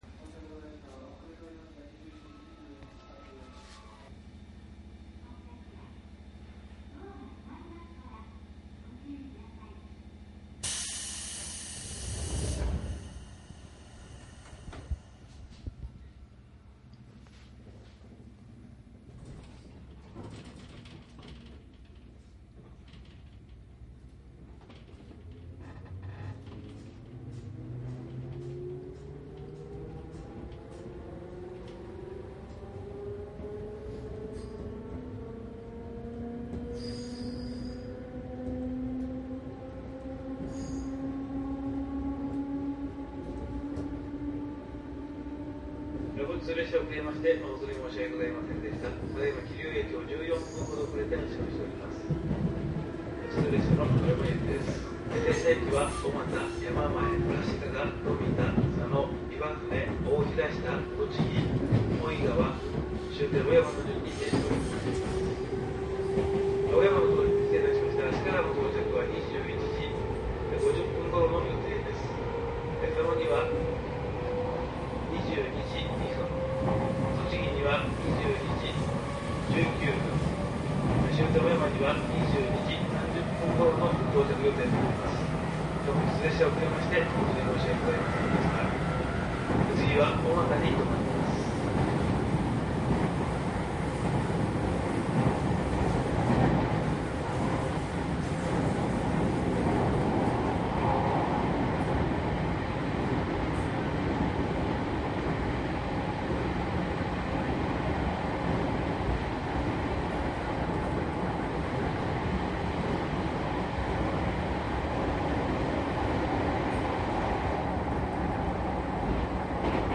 商品説明♪JR両毛線 鉄道走行音 ＣＤ ♪
かなり以前に録音した115系と211系電車録音 ＣＤです。
マスター音源はデジタル44.1kHz16ビット（マイクＥＣＭ959Ａ）で、これを編集ソフトでＣＤに焼いたものです。